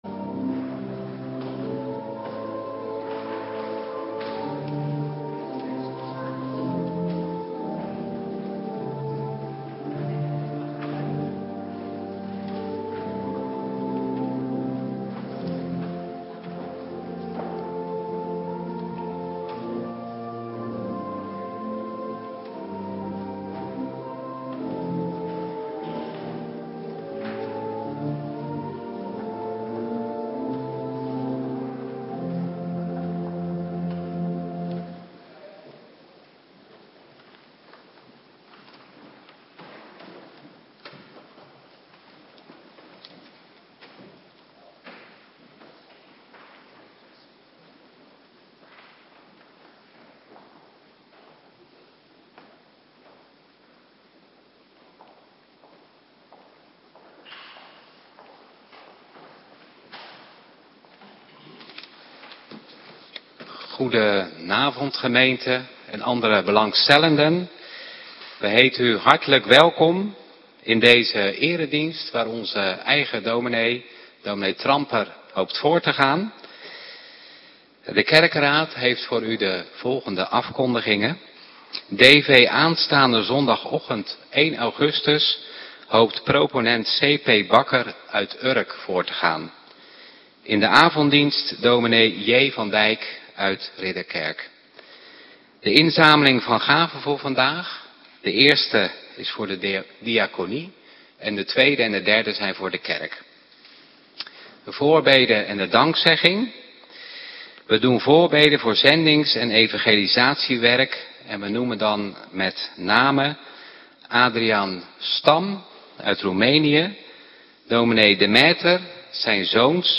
Avonddienst - Cluster A
Preek n.a.v. HC Zondag 3 Thema: Totaal verdorven, en toch... niet hopeloos